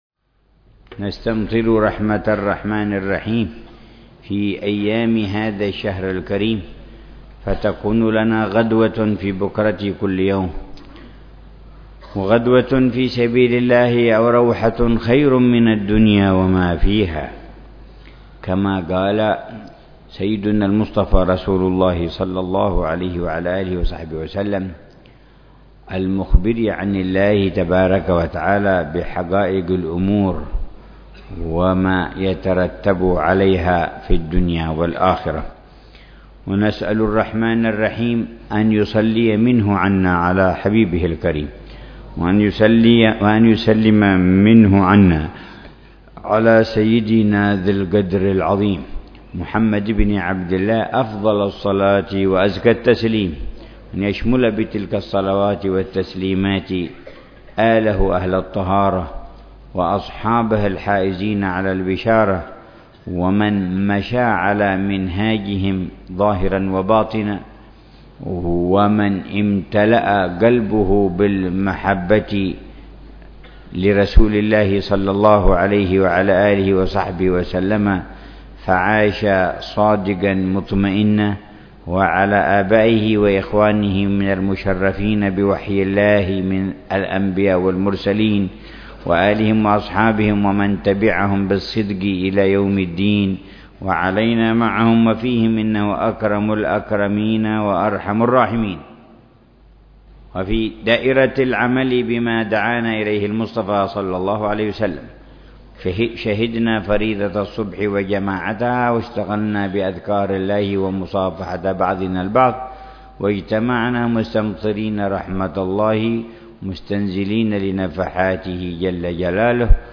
تفسير الحبيب عمر بن محمد بن حفيظ لسورة الفاتحة وقصار السور بدار المصطفى ضمن دروس الدورة الصيفية العشرين في شهر رمضان المبارك من العام 1435هـ.